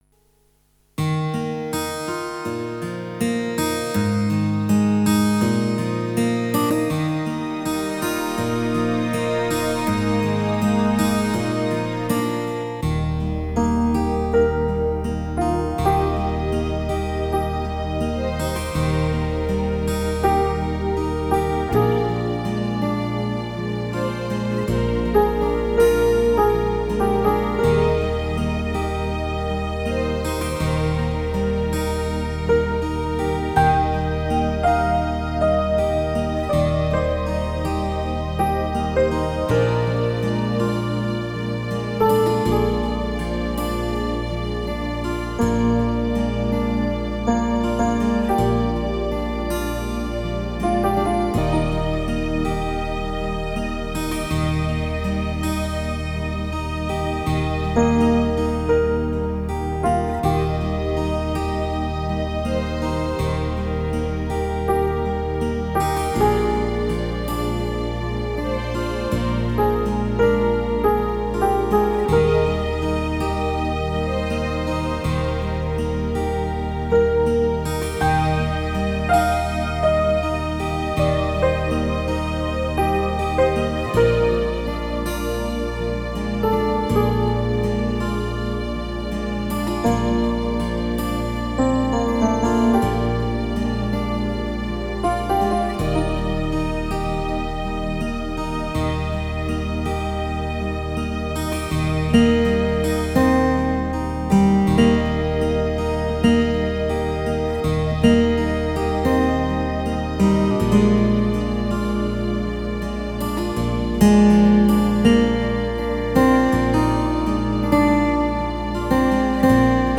Flauto di Pan a aa strumenti musicali
Playlist riguardante lo stile tonale "puro"